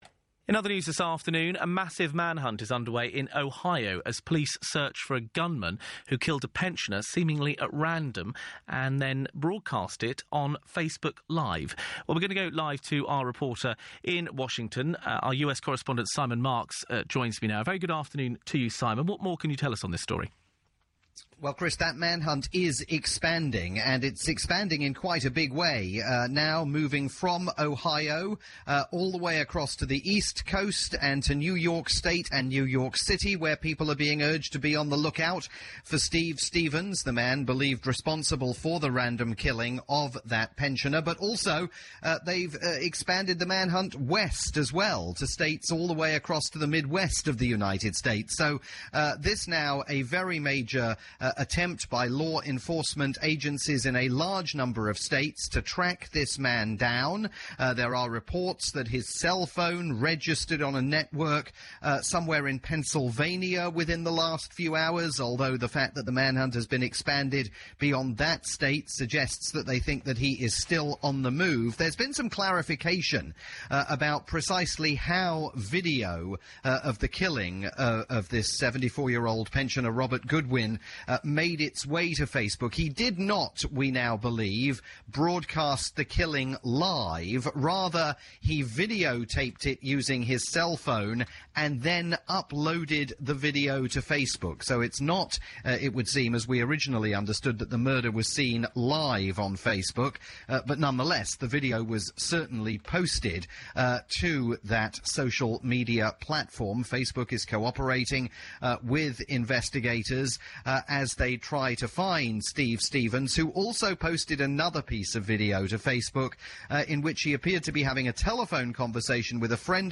latest update from Washington via the UK's rolling news station LBC London News.